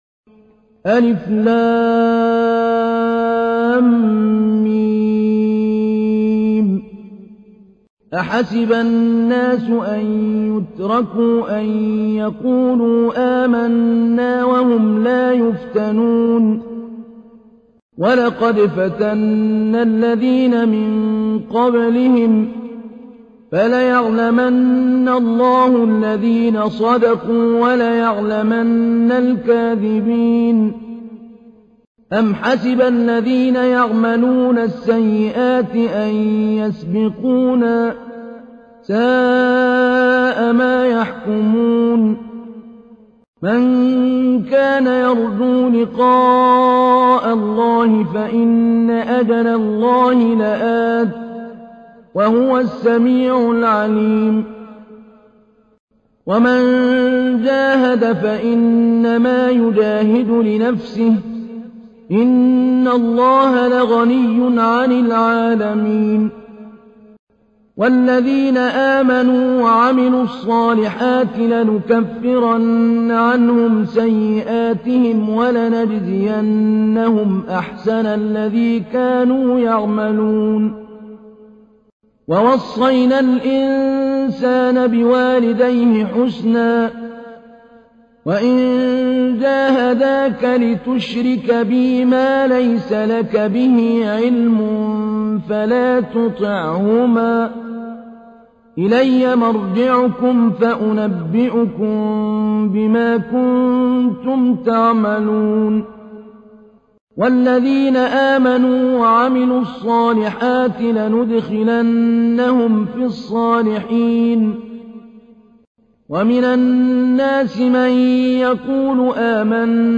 تحميل : 29. سورة العنكبوت / القارئ محمود علي البنا / القرآن الكريم / موقع يا حسين